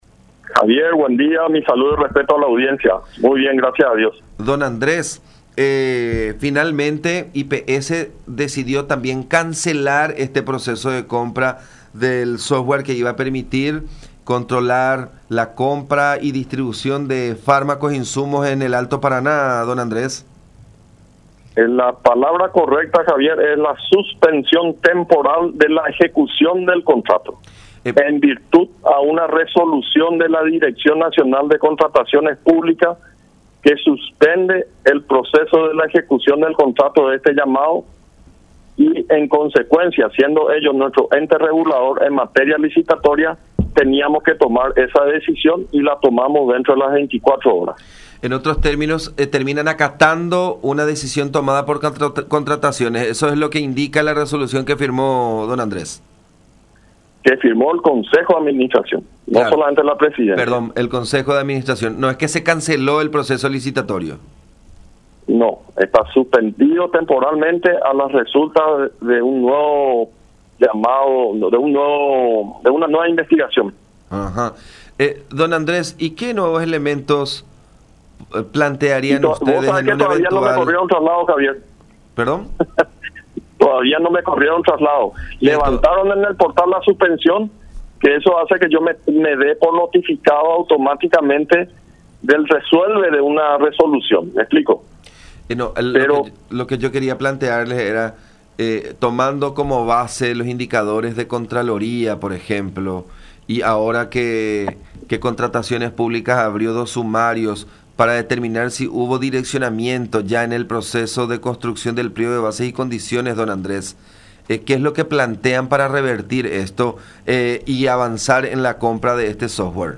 “Tomamos la decisión de suspender temporalmente la ejecución de la licitación, a las resultas de una nueva investigación. Nuestro ente regulador es Contrataciones Públicas y la decisión de esta suspensión temporal la tomaron el día martes”, aseveró el presidente de IPS, Andrés Gubetich, en conversación con La Unión.